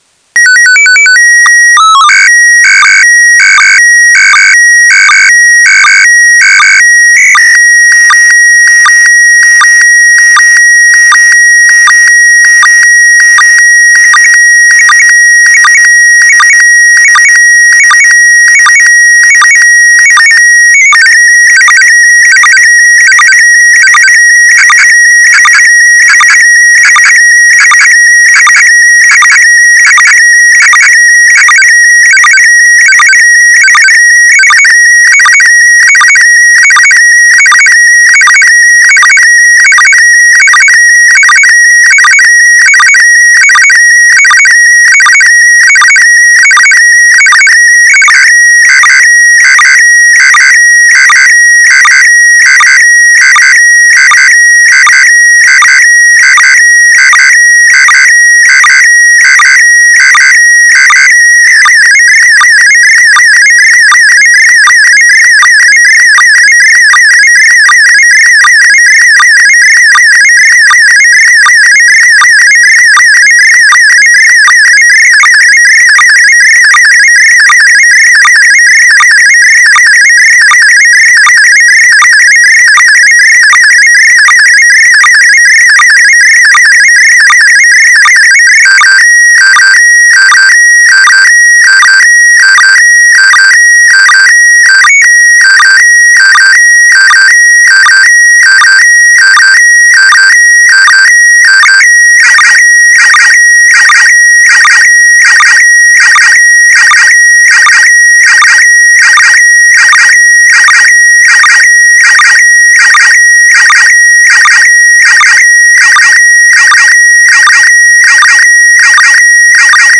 SSTV Funktionstest - mit Audio-Dateien
SSTV-Test-PD180-TestB.mp3